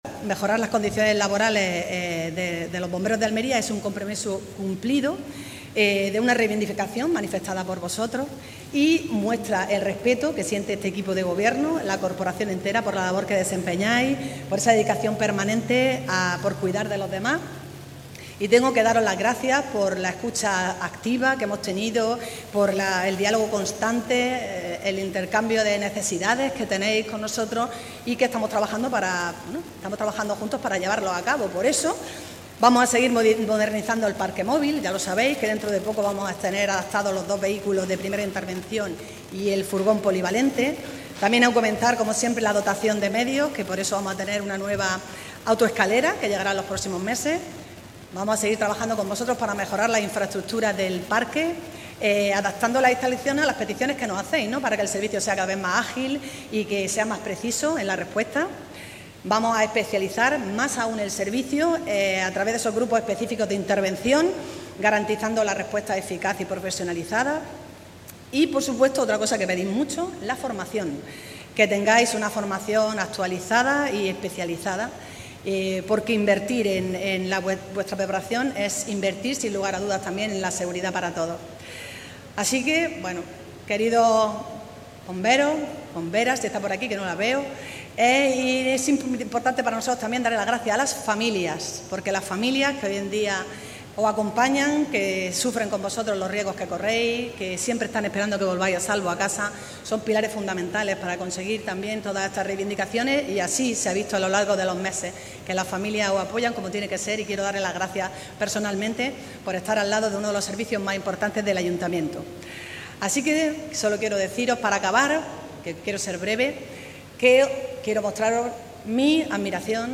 María del Mar Vázquez avanza la compra de una autoescalera de brazo articulado de 32 metros y la mejora de infraestructuras del parque durante la toma de posesión de 90 efectivos por promoción interna
Así lo ha avanzado durante la toma de posesión por promoción interna de cinco cabos y 85 bomberos como personal funcionario de carrera del grupo C, subgrupo C1.